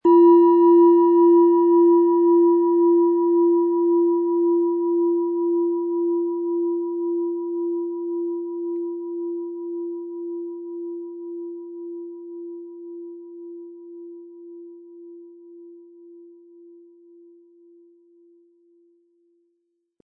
Tibetische Fuss-Herz-Bauch- und Kopf-Klangschale, Ø 13 cm, 320-400 Gramm, mit Klöppel
Beim Aufnehmen für den Shop spielen wir die Klangschale an und versuchen zu ermitteln, welche Bereiche des Körpers angesprochen werden.
Im Sound-Player - Jetzt reinhören können Sie den Original-Ton genau dieser Schale anhören.
Lieferung inklusive passendem Klöppel, der gut zur Planetenschale passt und diese sehr schön und wohlklingend ertönen lässt.
MaterialBronze